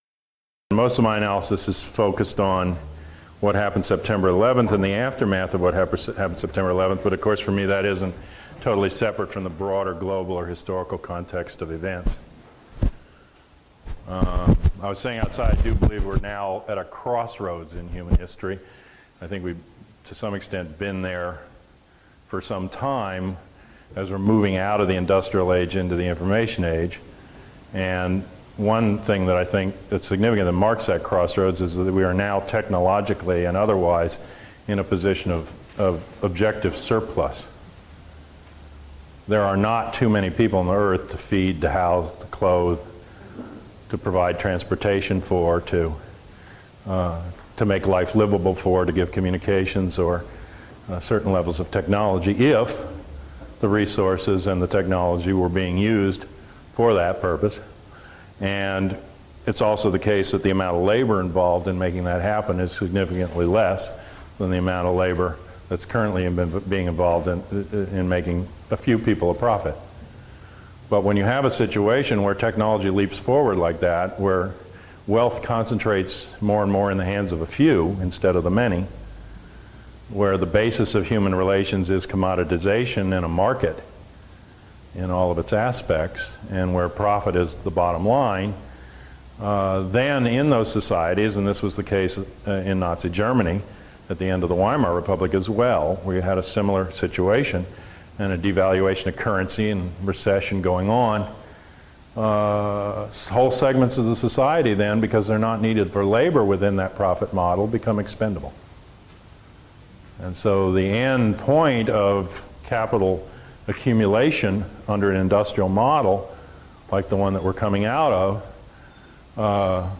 A talk at the University of Washington Ethnic Cultural Center.